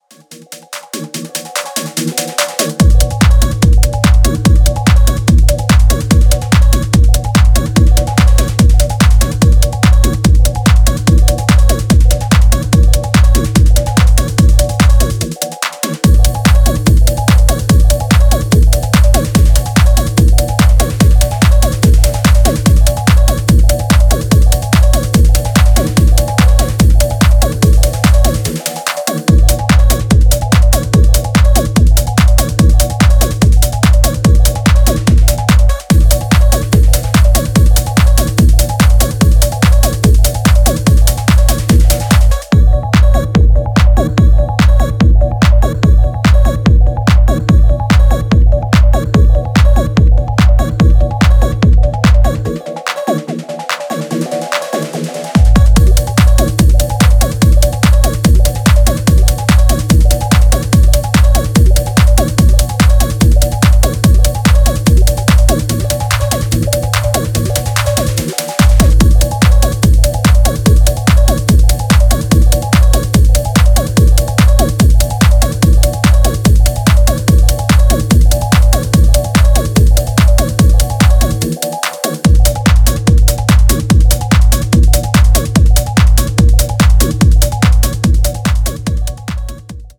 Techno